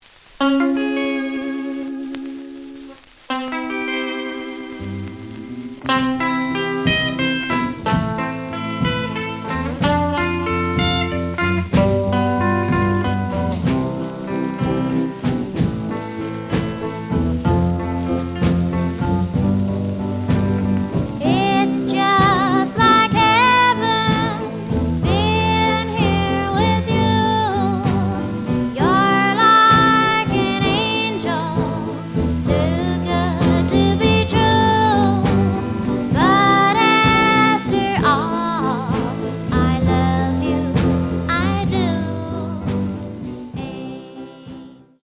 The audio sample is recorded from the actual item.
●Genre: Rhythm And Blues / Rock 'n' Roll
Looks good, but some noise on both sides.)